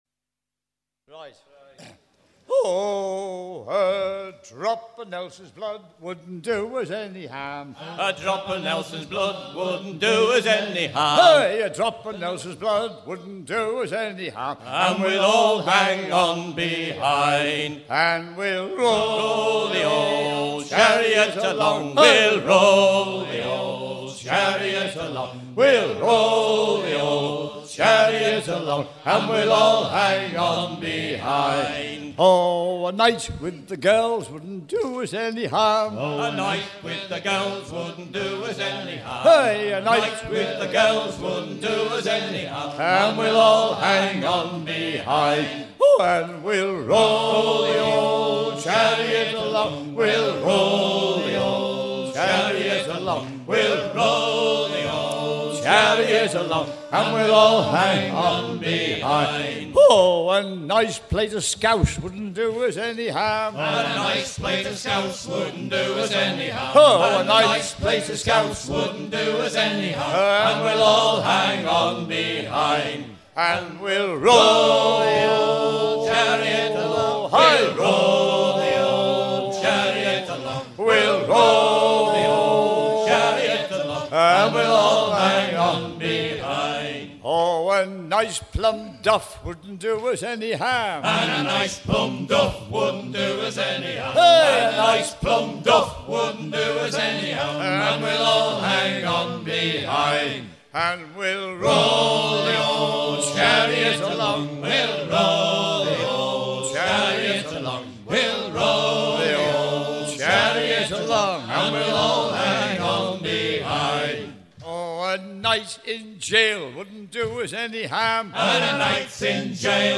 chant d'origine noir
Pièce musicale éditée